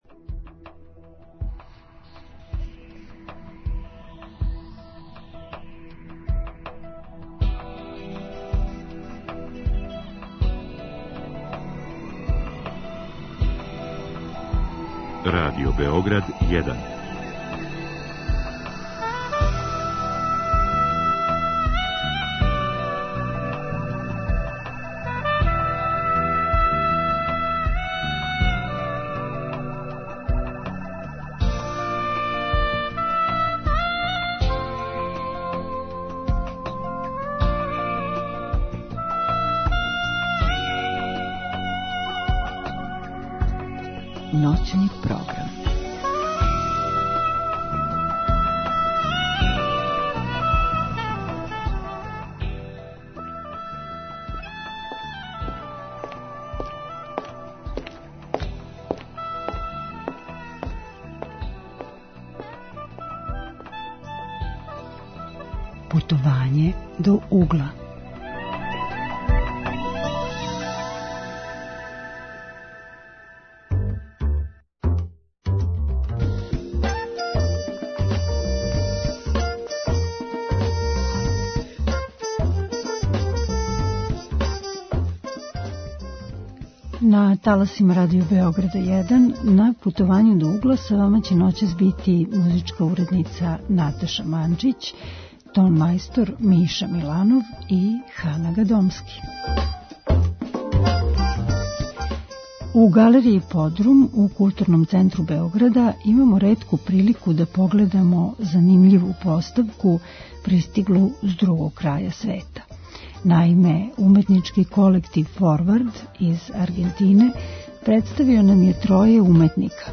Наши саговорници су аргентински уметници